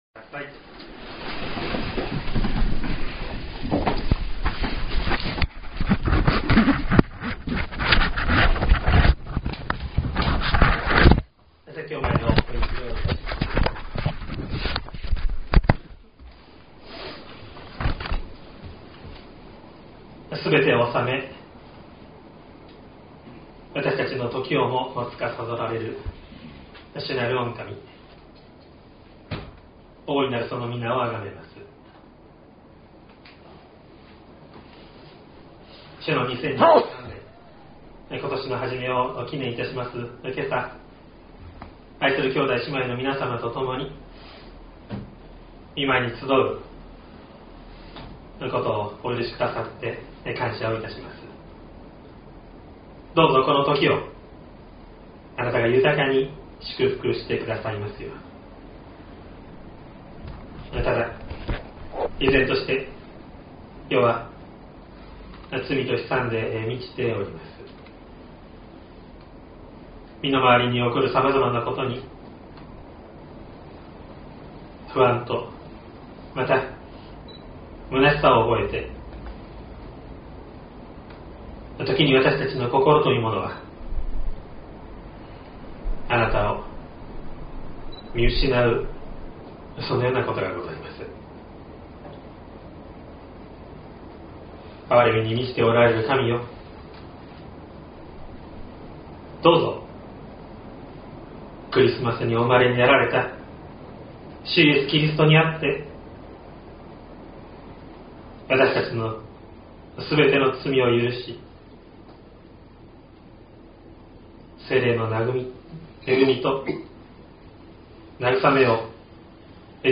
2023年01月01日朝の礼拝「神と共にある旅路の始め」西谷教会
音声ファイル 礼拝説教を録音した音声ファイルを公開しています。